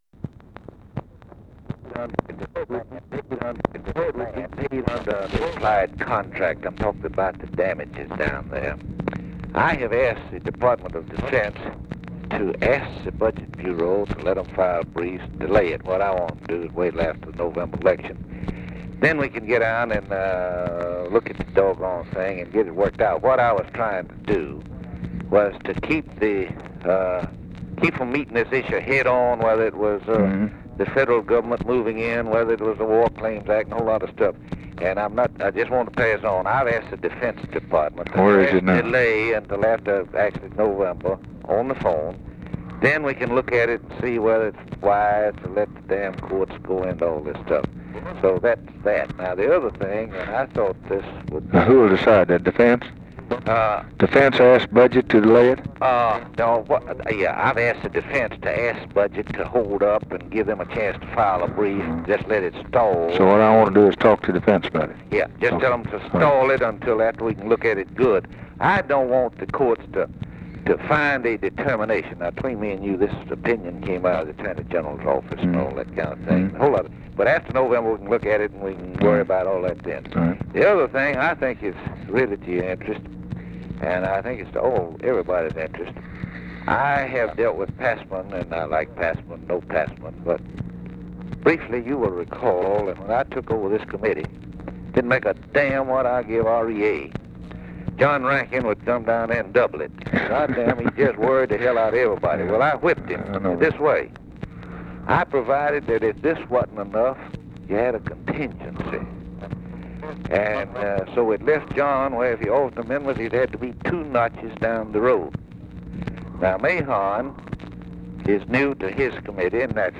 Conversation with JAMIE L. WHITTEN, May 26, 1964
Secret White House Tapes